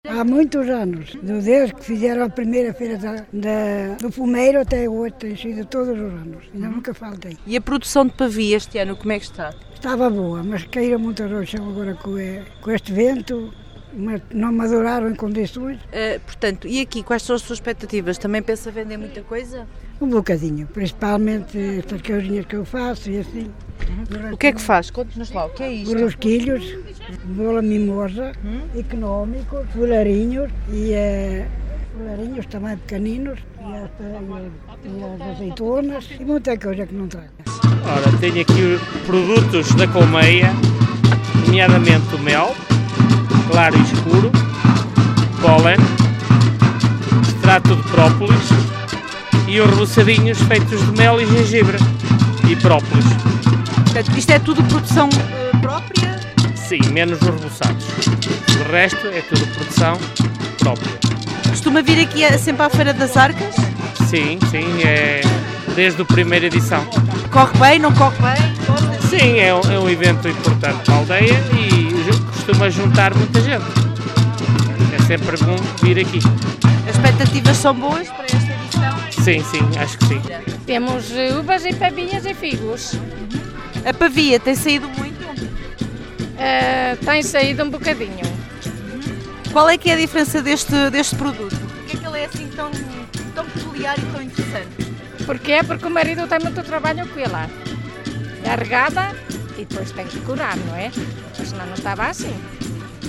Este fim-de-semana, decorreu a III edição da Feira da Pavia, na aldeia de Arcas, no concelho de Macedo de Cavaleiros.
A feira contou com cerca de 18 expositores, que não podiam estar mais satisfeitos, contando que a produção este ano esteve boa, apesar de alguns constrangimentos: